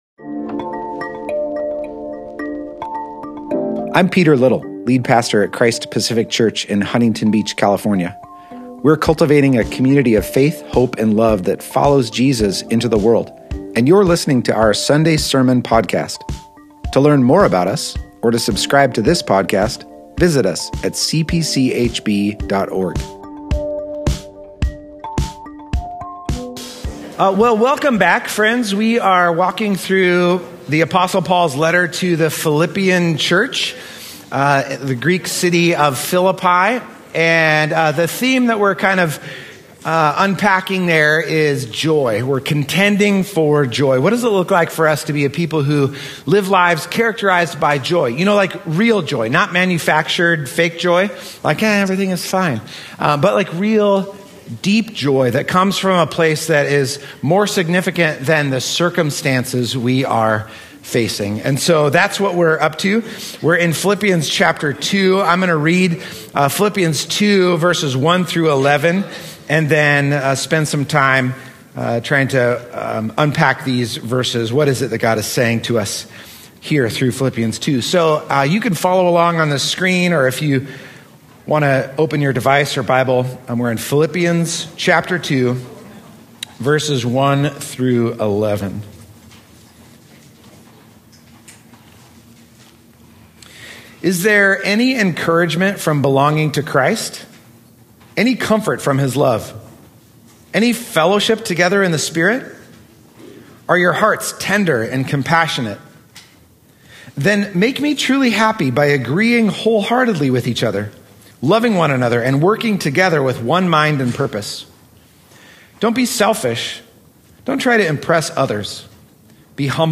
Join us as we continue in our sermon series, Contending for Joy.